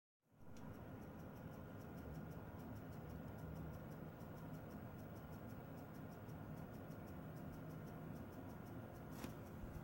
Komische klack Geräusche bei neuer RX 7900 XT
Unter Last (z.B. Videoschnitt in Davinci Resolve) macht die Karte extrem komische Knackgeräusche. Egal ob die Lüfter laufen oder Nicht.